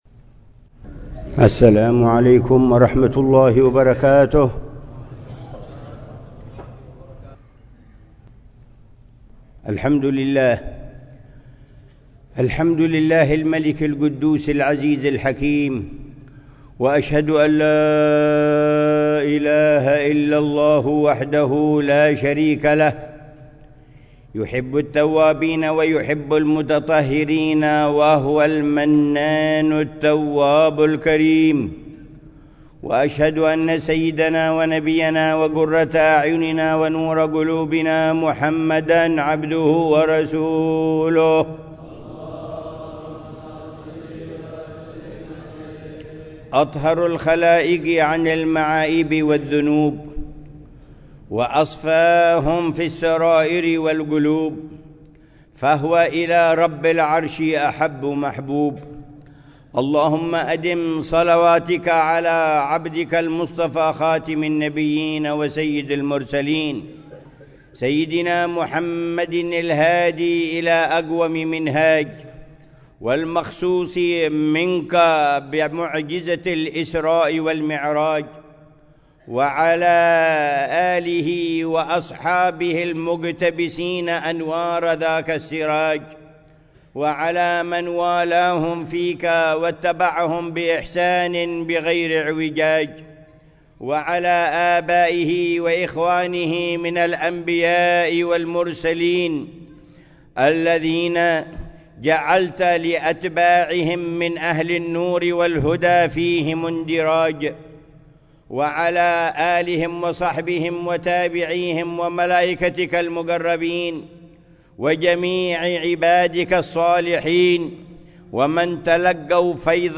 خطبة الجمعة للعلامة الحبيب عمر بن محمد بن حفيظ في جامع الإيمان بتريم، 10 رجب الأصب 1446هـ بعنوان: